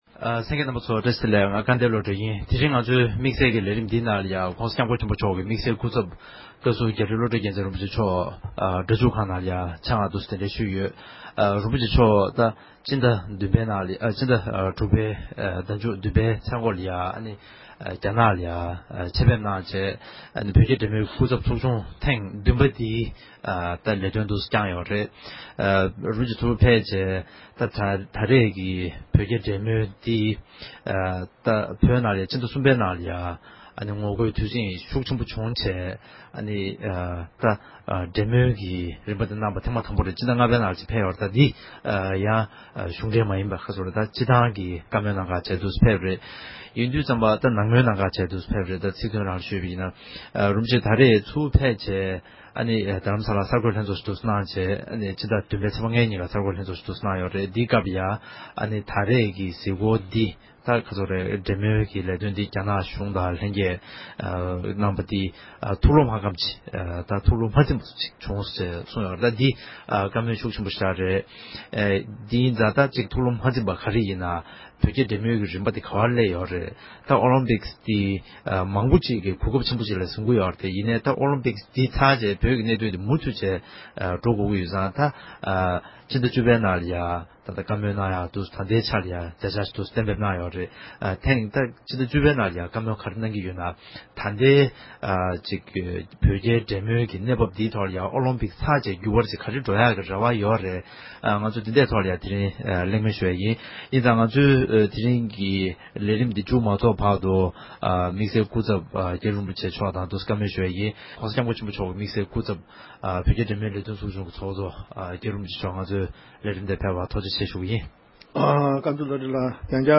དམིགས་བསལ་སྐུ་ཚབ་མཆོག་ནས་འདི་ག་ཨེ་ཤེ་ཡ་རང་དབང་རླུང་འཕྲིན་ཁང་གི་ལས་རིམ་ཐོག་གླེང་མོལ་གནང་བ།